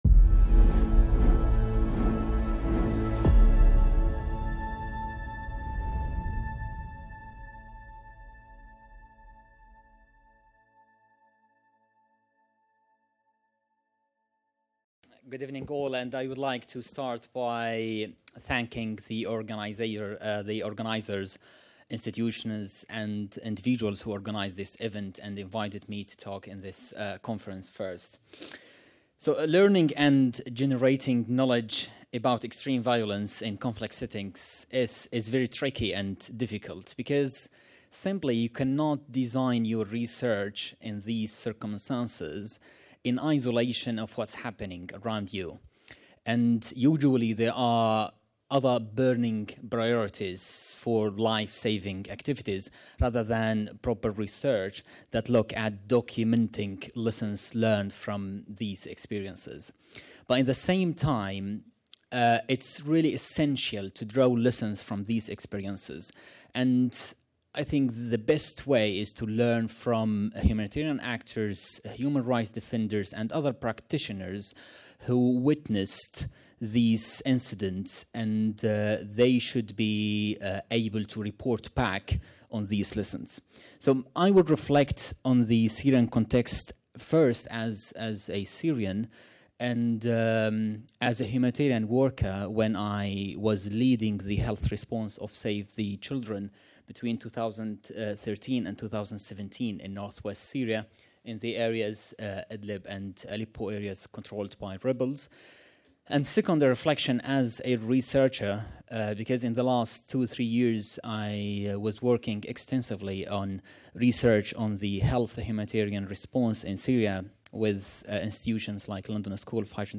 République démocratique du Congo Théâtres de guerres, d’un génocide, de crimes de masse, la Syrie, le Rwanda et la République démocratique du Congo sont au cœur du colloque international organisé par